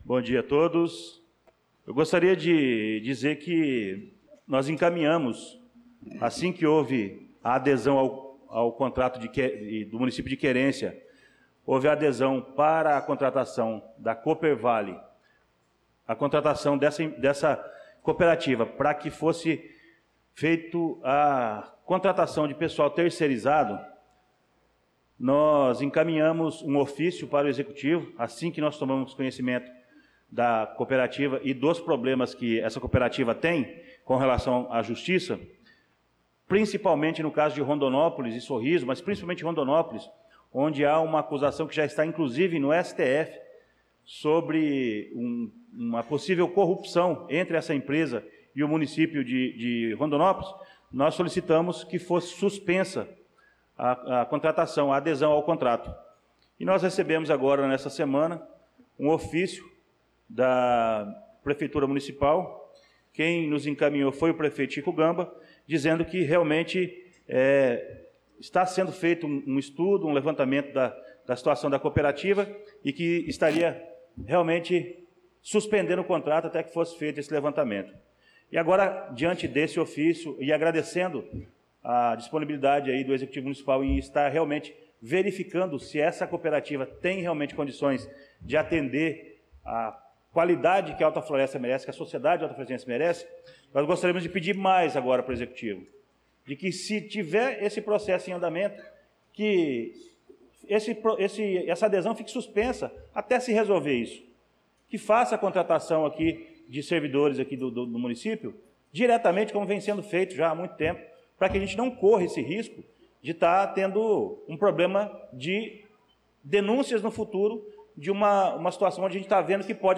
Pronunciamento do vereador Luciano Silva na Sessão Ordinária do dia 05/05/2025